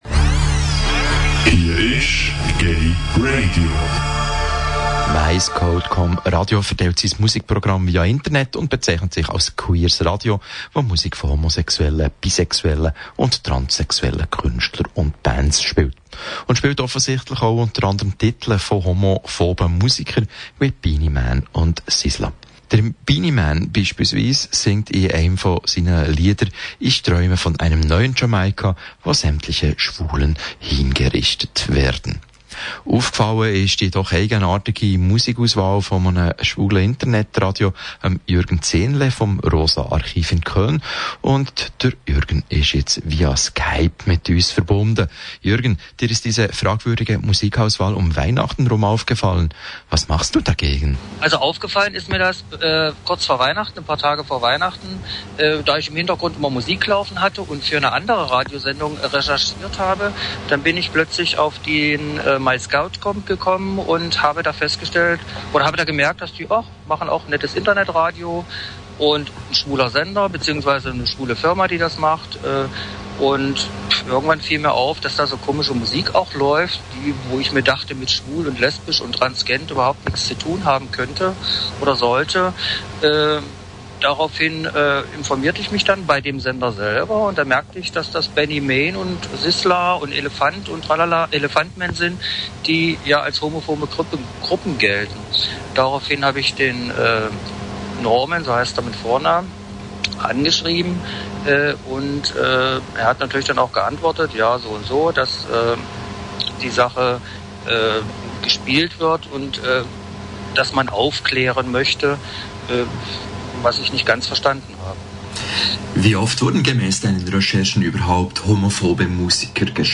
Ein knapp neunminütiges Interview * zum homophoben LSVD-Fall, der sich nun aber wirklich langsam zum Skandal (was er für mich von Anfang an war) entwickelt, gab ich auf dessen Anfrage dem schweizer gayRadio. Natürlich gab es zum Ende hin auch ein paar Infos über das Rosa Archiv selbst.